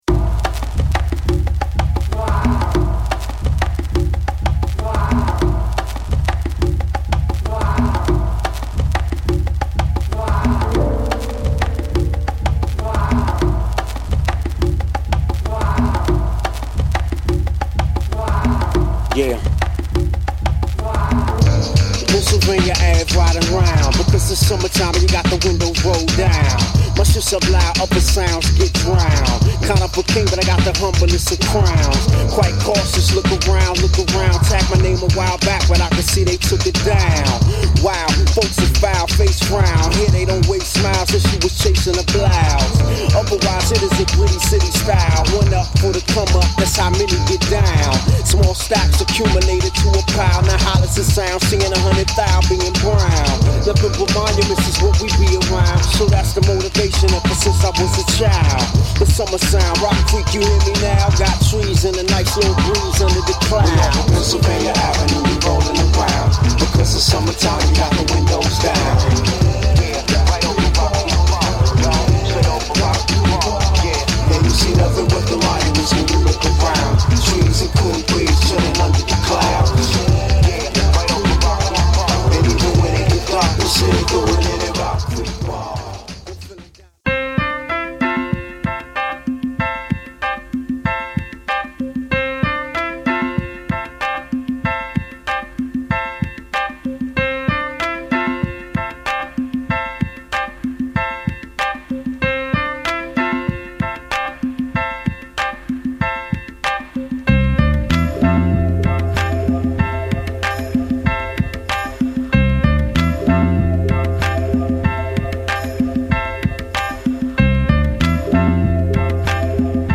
メロウな鍵盤とエモーショナルな展開が心地よいA2
タイトで引き締まったドラムにウッディーなベース、そこへフュージョン感抜群なウワモノが流れ行くA4